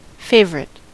Ääntäminen
US : IPA : [ˈfeɪ̯v.ɹɪt]